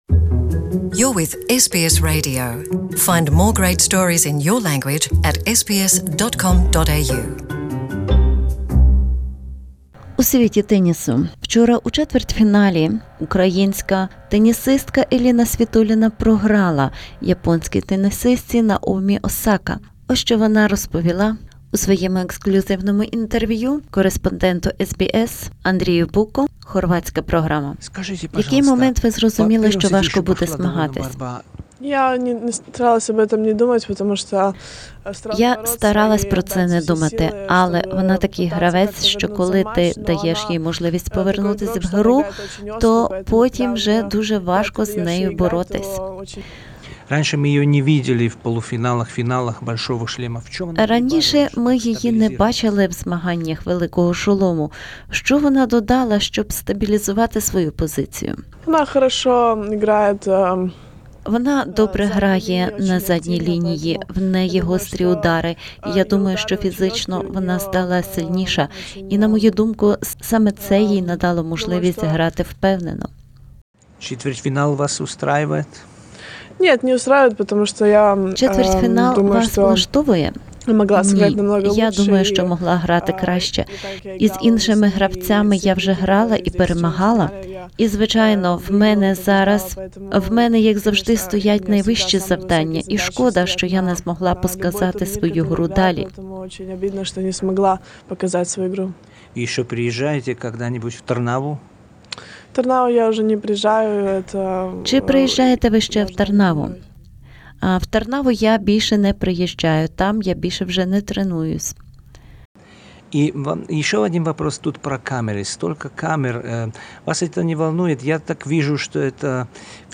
Exclusive interview with Ukrainian tennis player Elina Svitolina at Australia Open 2019.